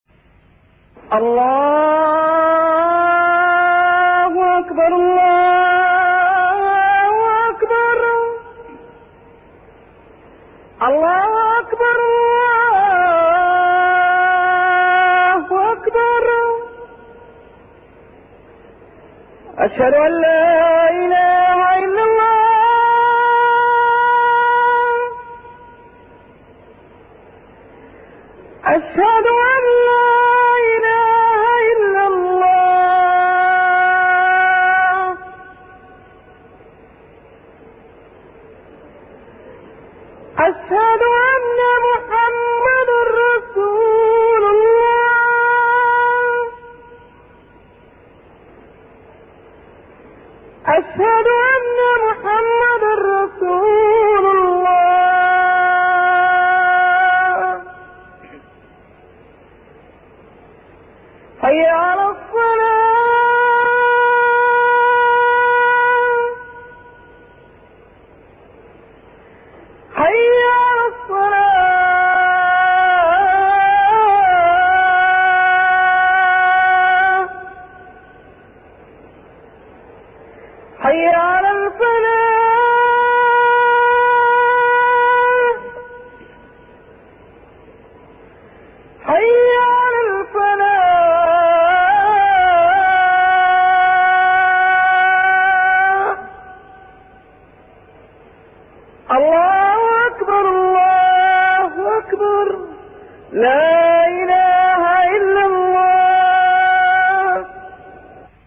أناشيد ونغمات
عنوان المادة أذان-11